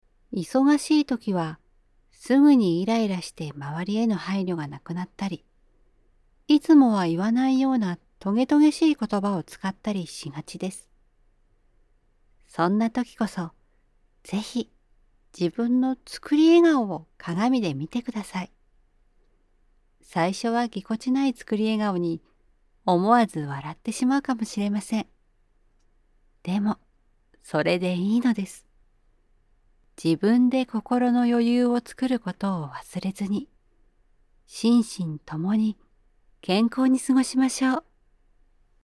My voice range is mid-range and has a gentle quality without any quirks.
– Narration –
female75_4.mp3